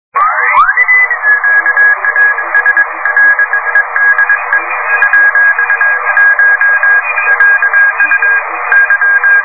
Signaux entendus sur RS13, bande 2m, le 05/05/2002 vers 2045 UTC :
une station en télégraphie -